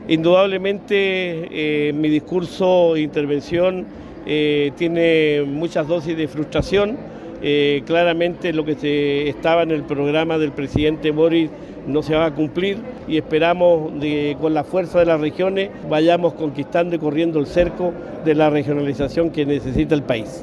cuna-cuvertino-x-asuncion.mp3